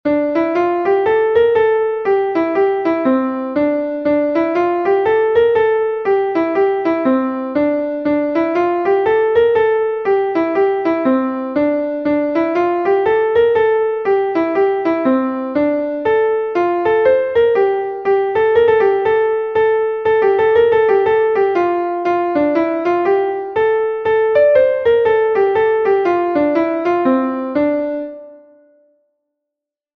Gavotte Diaouled Ar Menez IV is a Gavotte from Brittany recorded 1 times by Diaouled Ar Menez